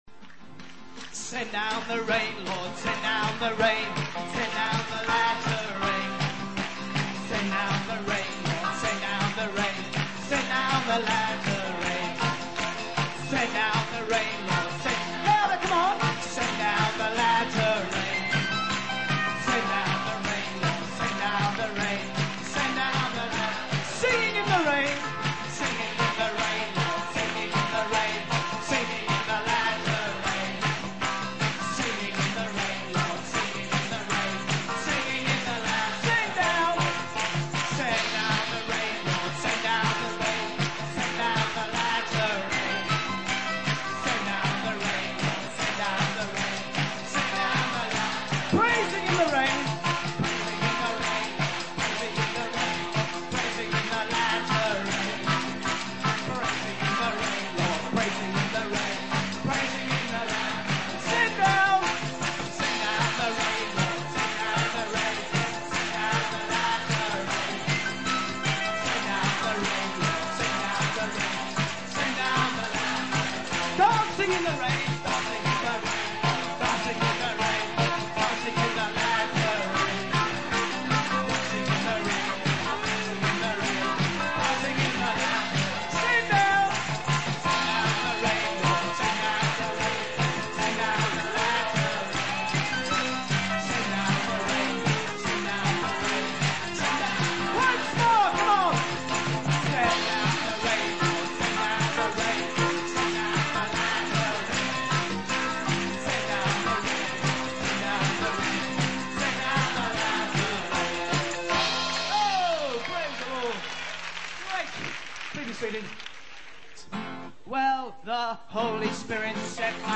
Harvest Celebration 1981
The speaker emphasizes the importance of paying the price and seeking God's face for revival in Britain. He asks the audience if they are in God's race and challenges them to be on fire for God, devoted to Him in every aspect of their lives.